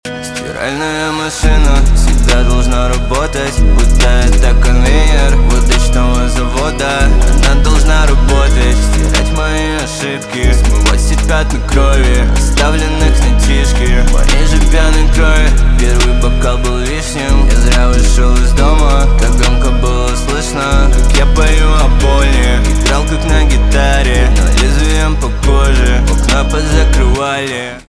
альтернатива
грустные , печальные , битовые
спокойные